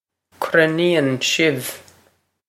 cruinníonn sibh krin-een shiv
Pronunciation for how to say
This is an approximate phonetic pronunciation of the phrase.